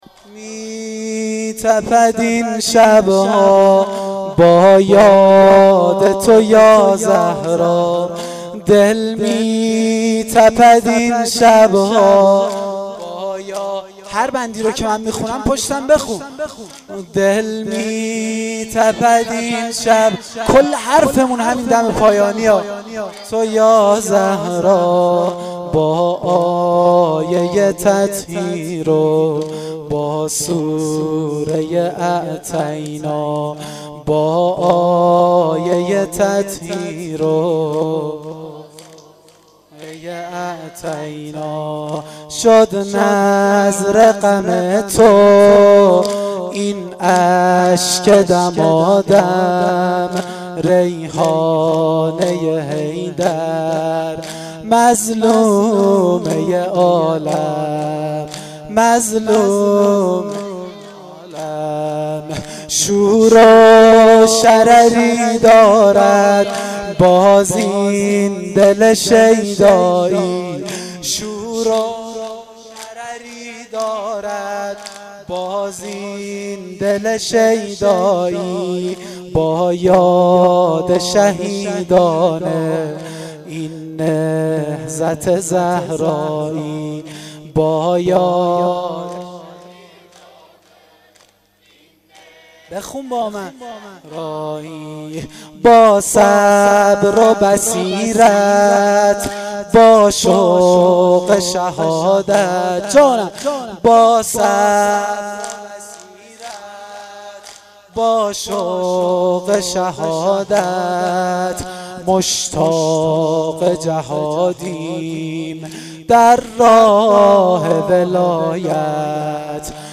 دم پایانی شب دوم فاطمیه